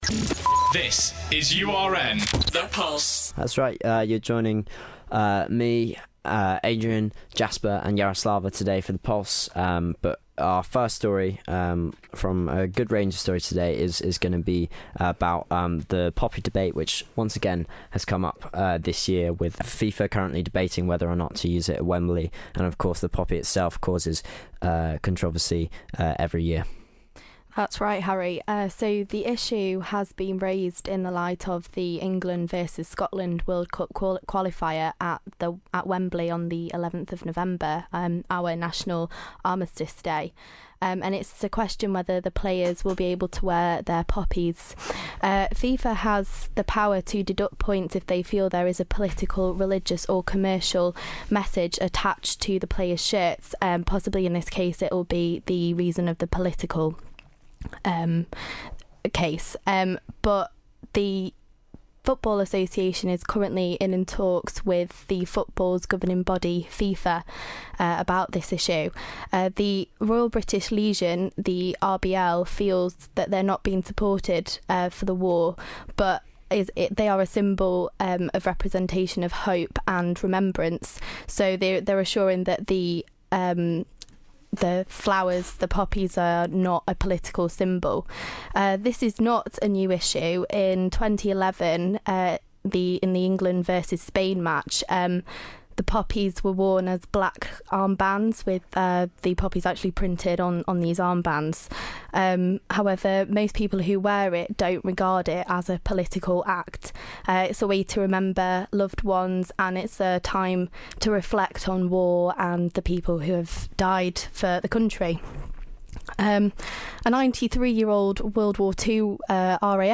The Tuesday Pulse team discussion whether or not the poppy is a symbol of controversy and should be worn publicly .